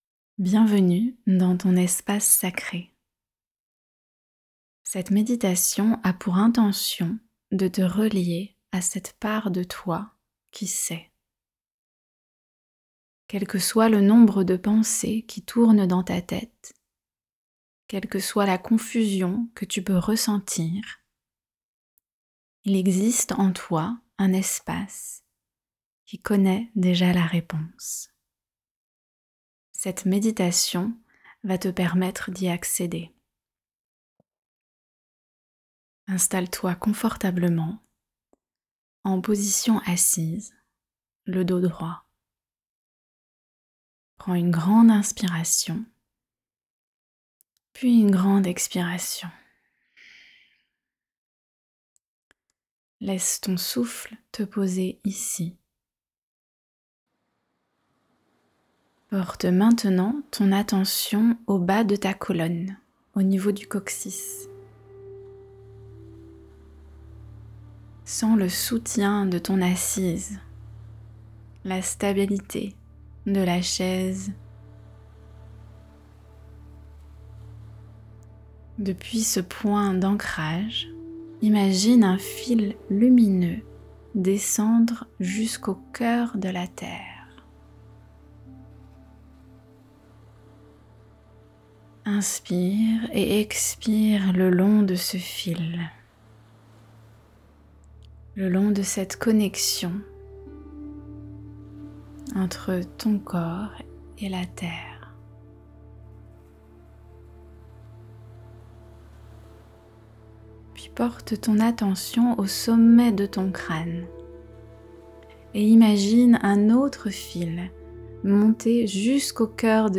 meditation-guidee-la-reponse-est-la.mp3